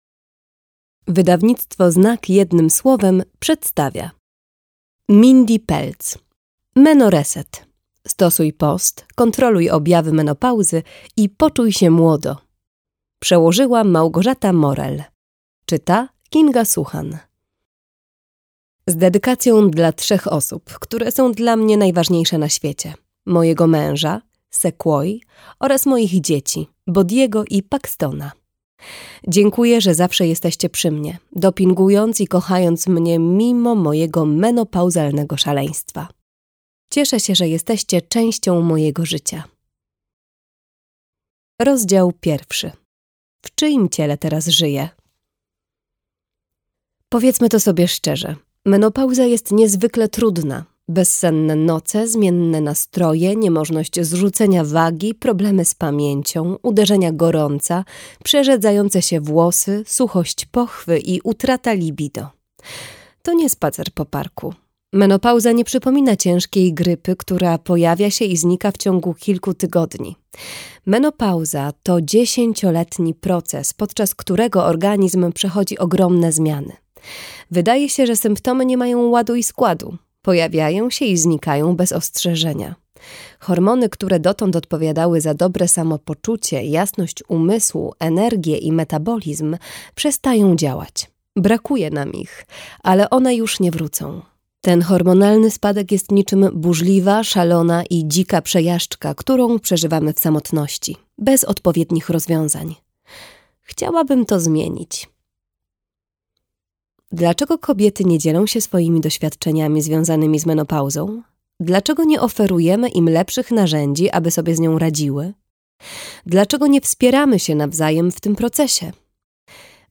Stosuj post, kontroluj objawy menopauzy i poczuj się młodo - Pelz Mindy - audiobook